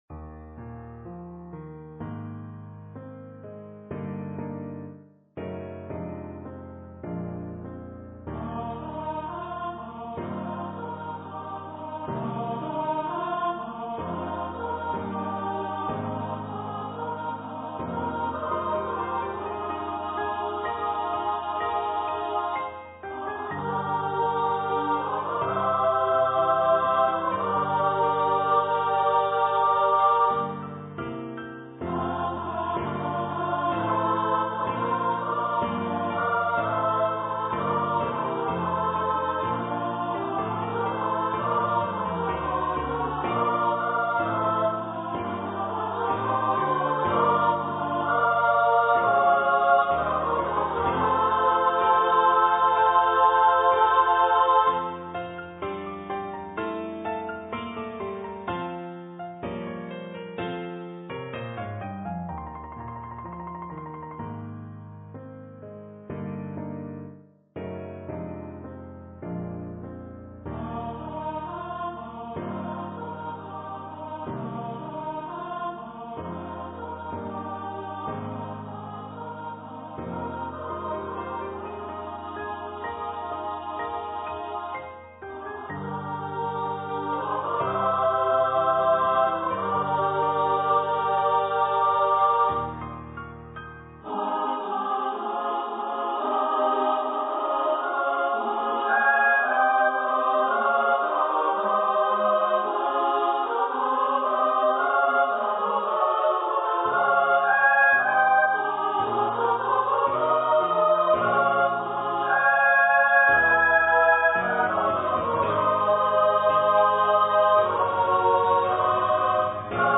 for SSA choir
Carols for Christmas
(Choir - 3 part upper voices)